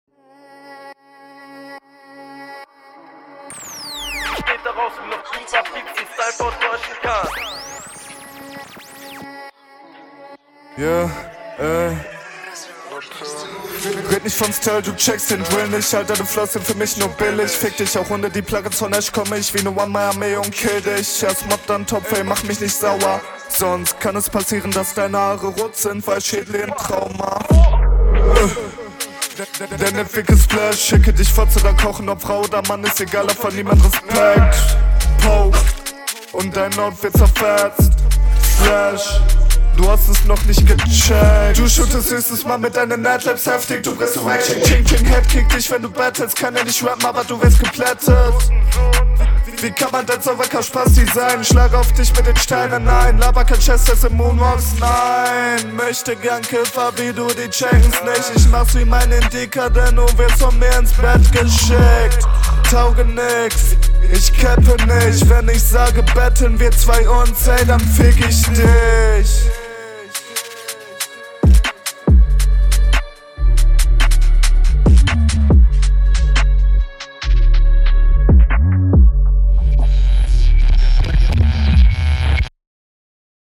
Flowst echt gut auf dem Beat. mIx n master ist auch Fresh.